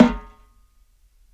SNARE OFF.wav